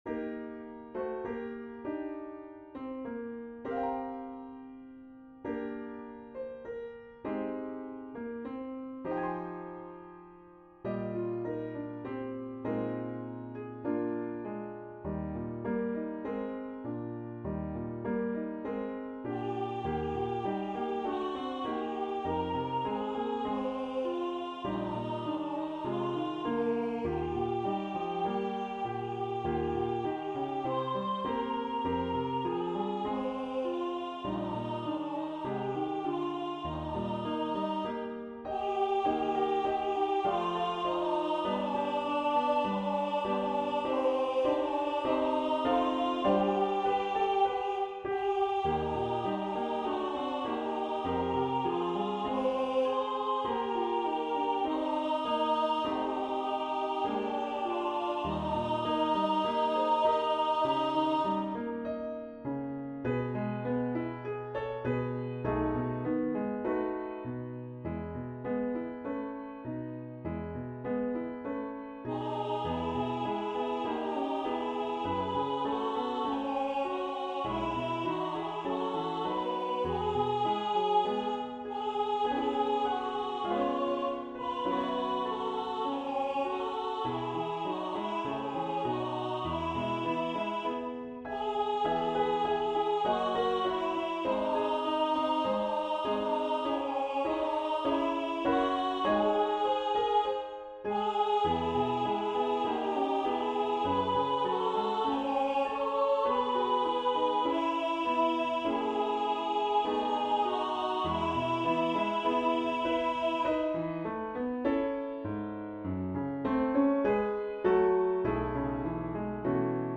Voicing/Instrumentation: SA , Duet